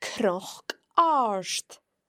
Let’s have a look at how the consonants cn are pronounced in Gaelic.